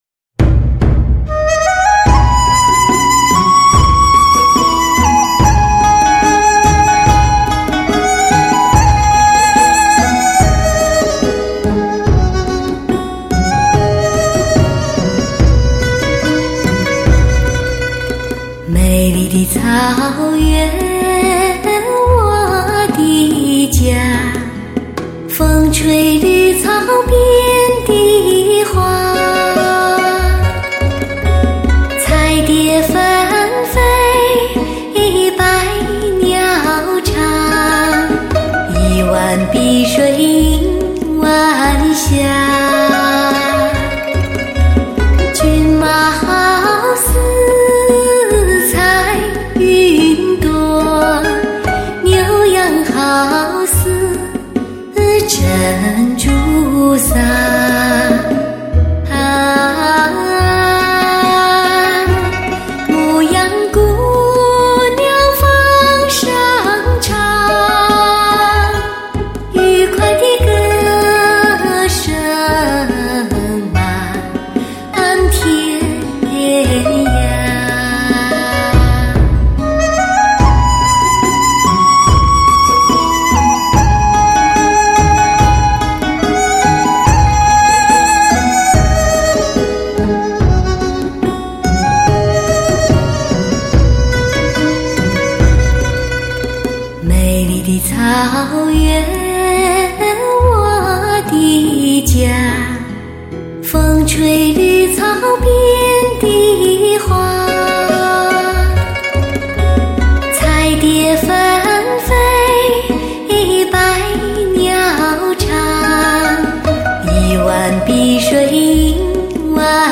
精彩民曲尊贵之声，融传统于现代，予经典于流行。
清新音色，磁性迷人歌喉，一声声，一首首，如同一个动人的传说，让人产生遐想和幻
为低音质MP3